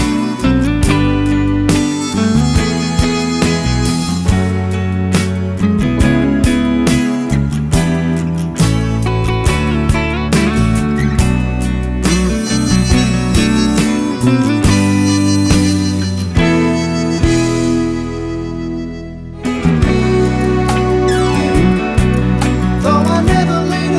no Backing Vocals Soundtracks 3:27 Buy £1.50